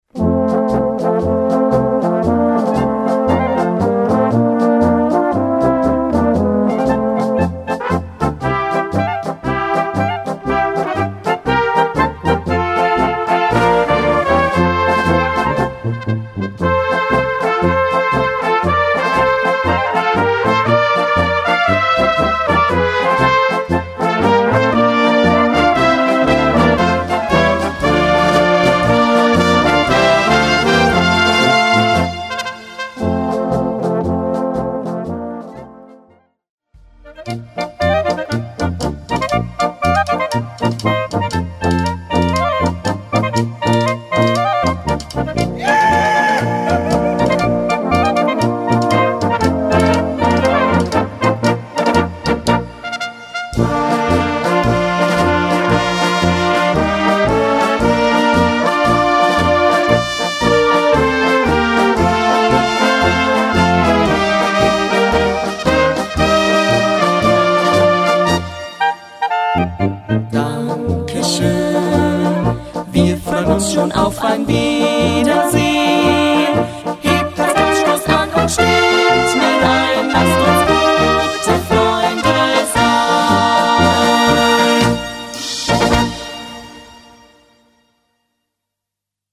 Gattung: Polka mit Text
Besetzung: Blasorchester